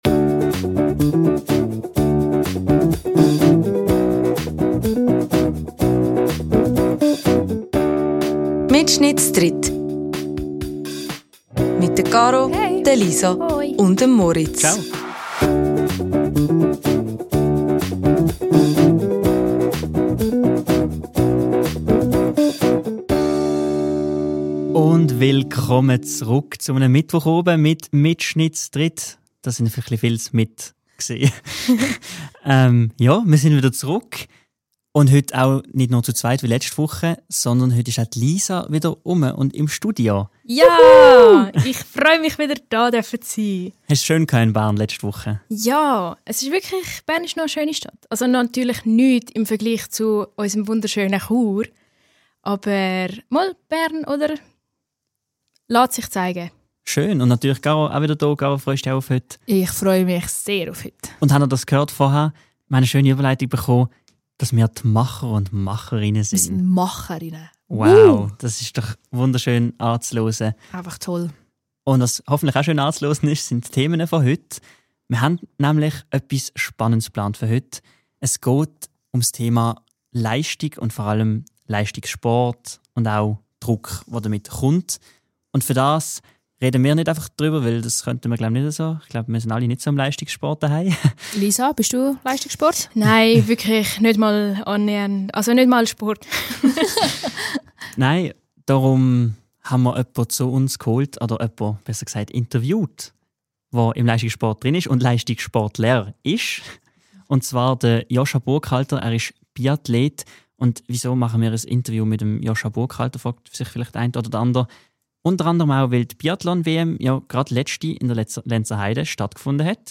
Wieder in voller Bsetzig und sogar mit emene Special Guest wageds die drüü Hosts in en neui Folg. En Profi Biathlet verzellt vo sine Erfahrige mit Leistigssport, es gid aber au neui Sportarte z entdecke und Dialektwörter zum lerne.